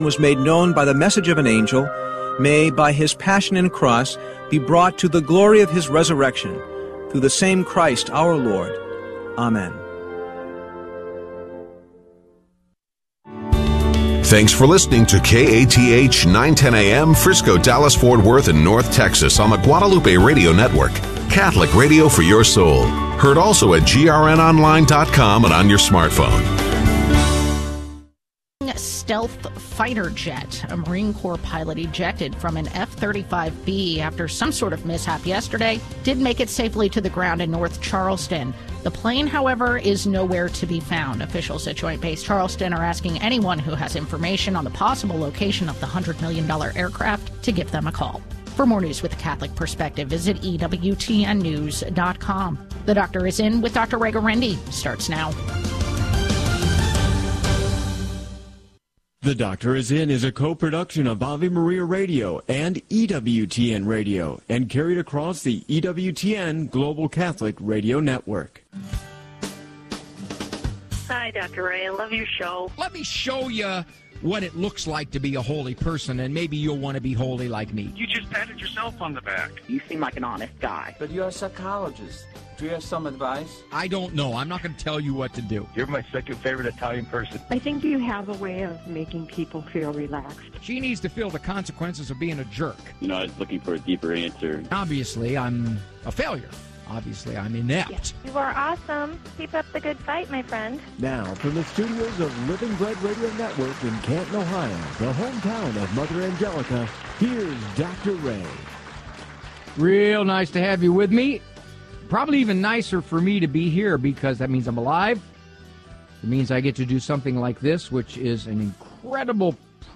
One hour of solid, Catholic conversation for your Monday afternoon. Focuses on issues pertinent to North Texas Catholics.